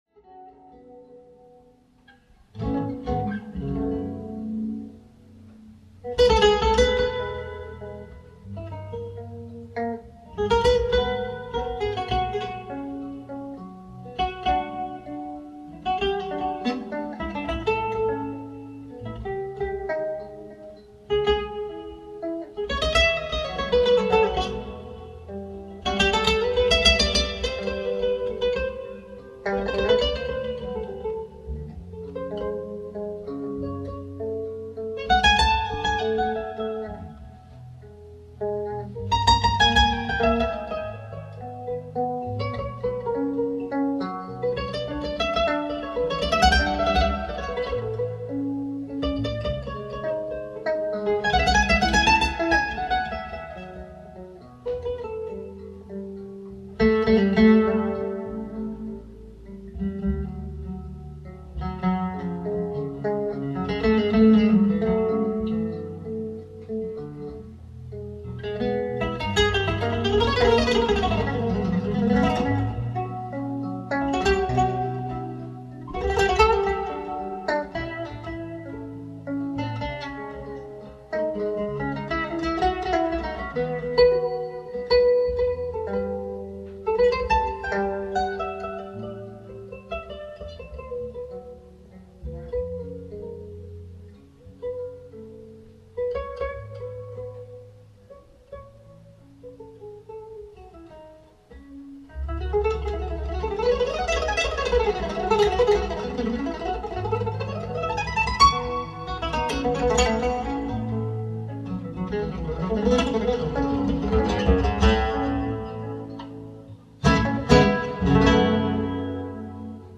ライブ・アット・ケンブリッジ、マサチューセッツ 08/10/1987
※試聴用に実際より音質を落としています。